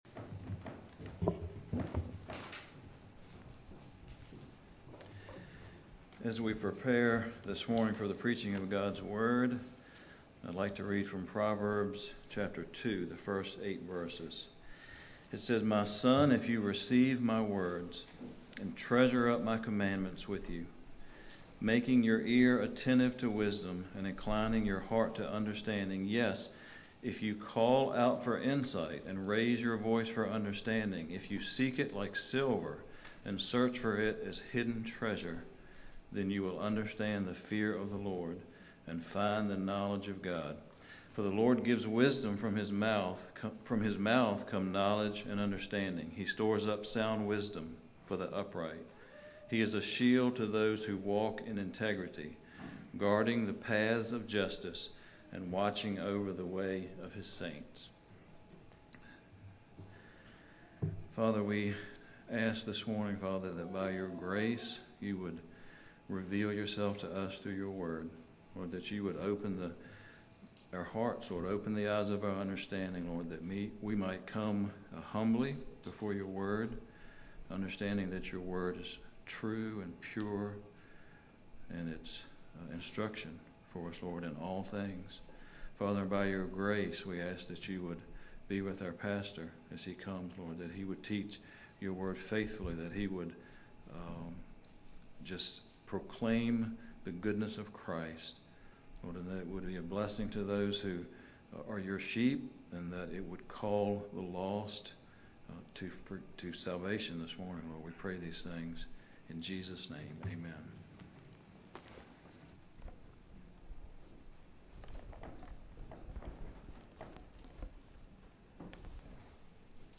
Greatest Sermon Ever Preached: Prayer That Pleases God (Mt. 6:5-8) – Grace Harvest Bible Church